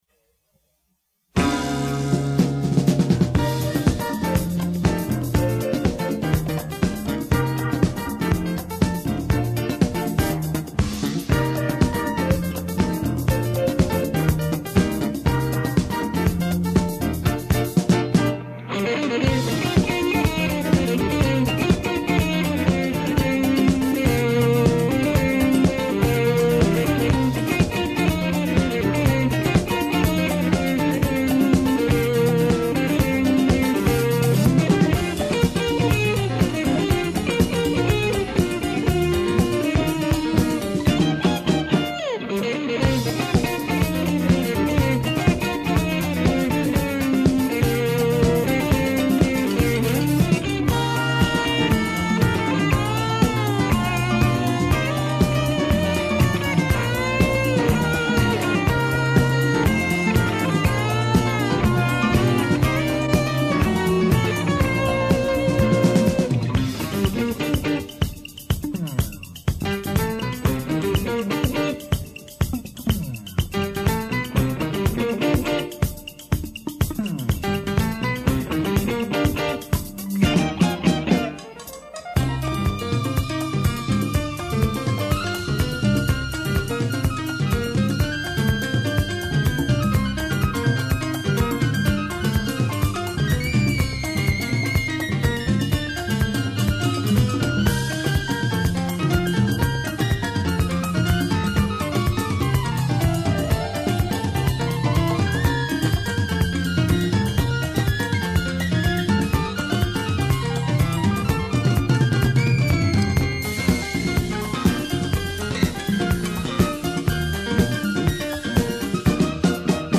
스튜디오 라이브 음원입니다.